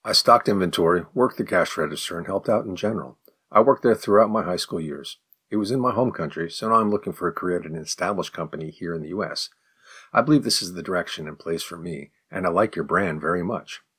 04_advanced_response_fast.mp3